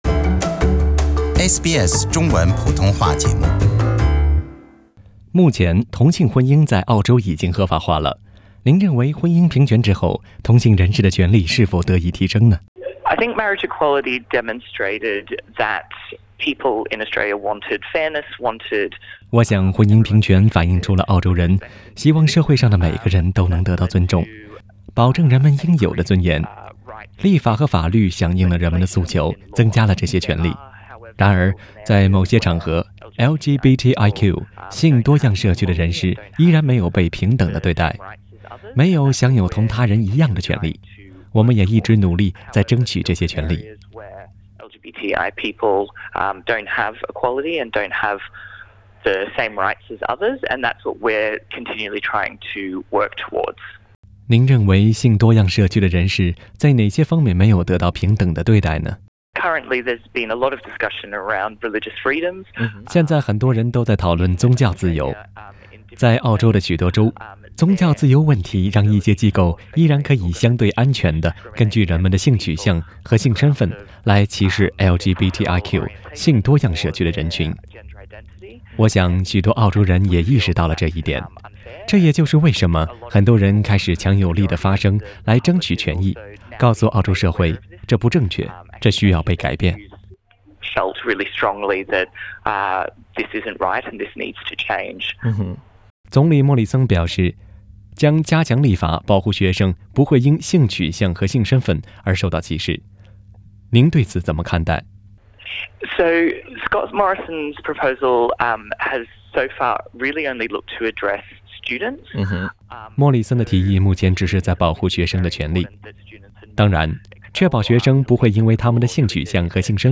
专访维州同性游说团体：同性学生和教师是否会得到保护？